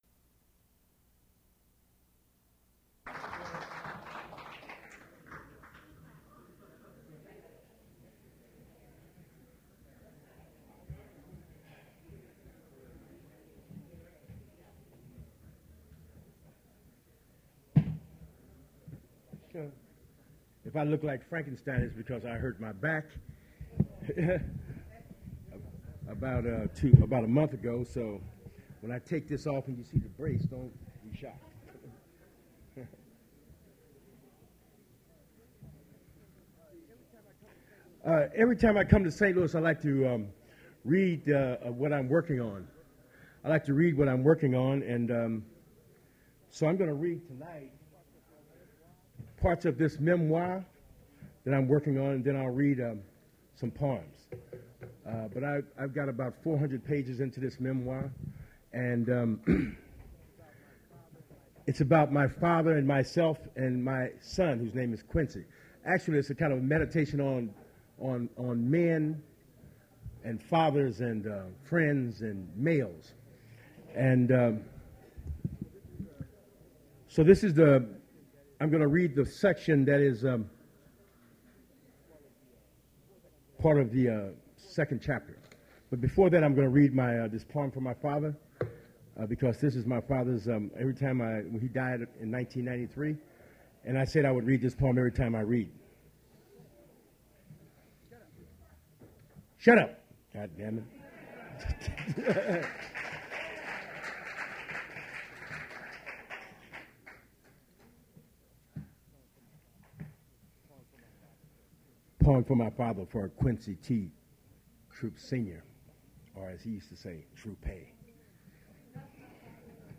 Poetry reading featuring Quincy Troupe
Attributes Attribute Name Values Description Quincy Troupe poetry reading at Duff's Restaurant.
Source mp3 edited access file was created from unedited access file which was sourced from preservation WAV file that was generated from original audio cassette. Language English Identifier CASS.778 Series River Styx at Duff's River Styx Archive (MSS127), 1973-2001 Note Large chunk of prose from 06:39 to 30:28.